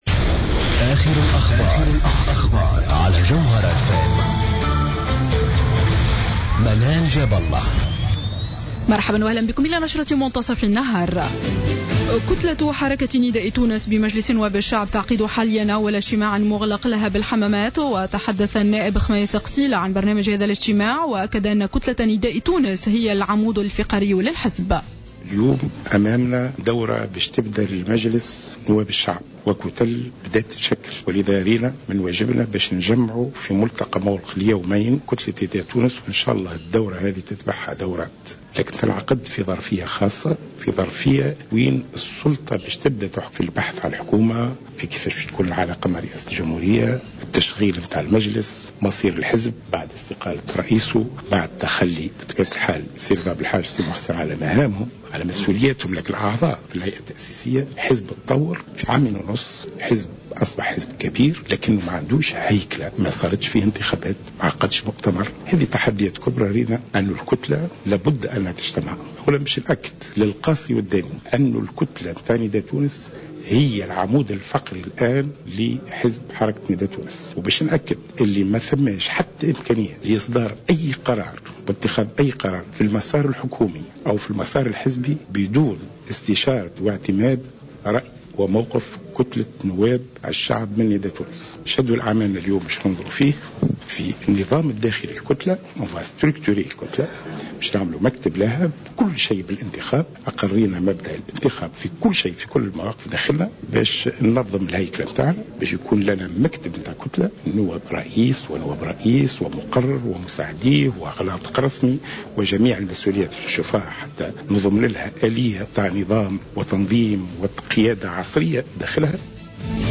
نشرة أخبار منتصف النهار ليوم السبت 02-01-15